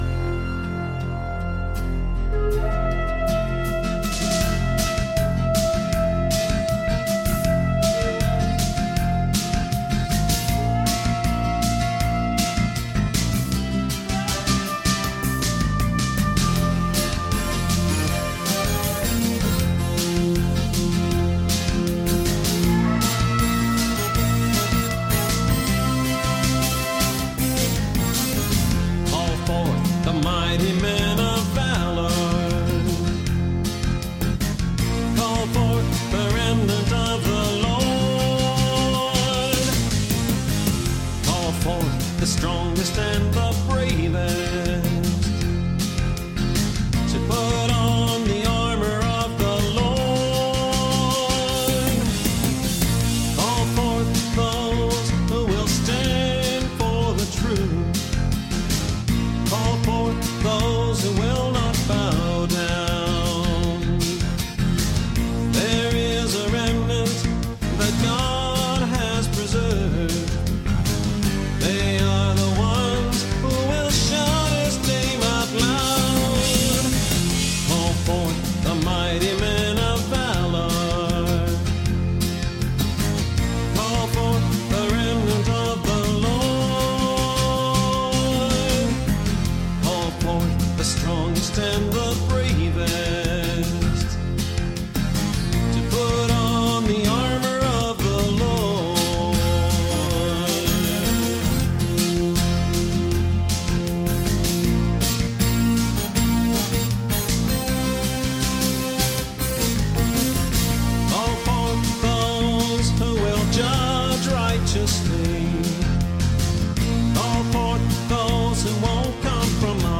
On this clip, you can still hear the reprise.
rams horns